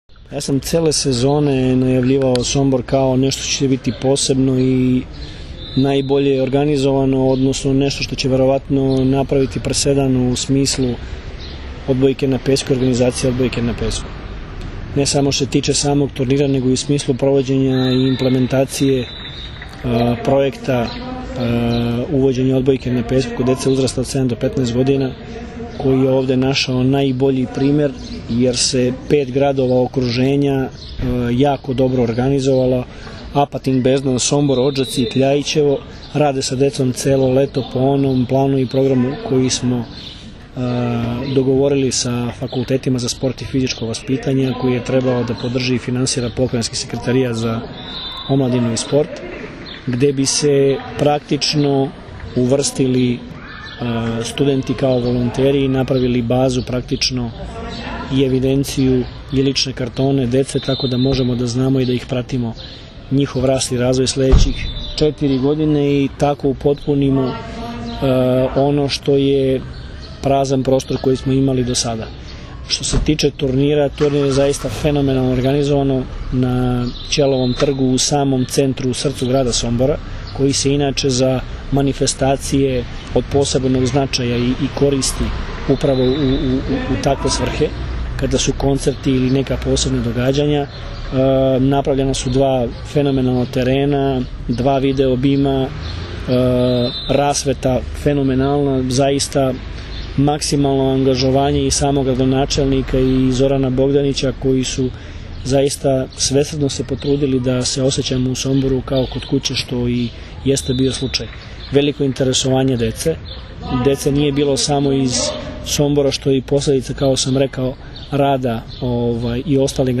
IZJAVA VLADIMIRA GRBIĆA